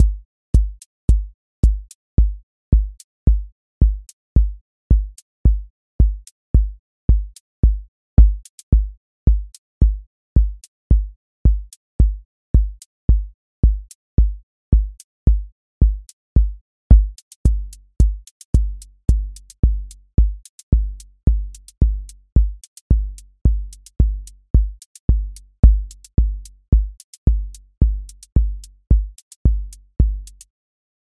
QA Test — Four on Floor
Four on Floor QA Listening Test house Template: four_on_floor April 18, 2026 ← Back to all listening tests Audio Four on Floor Open MP3 directly Selected Components macro_house_four_on_floor voice_kick_808 voice_hat_rimshot voice_sub_pulse Test Notes What This Test Is Four on Floor Selected Components macro_house_four_on_floor voice_kick_808 voice_hat_rimshot voice_sub_pulse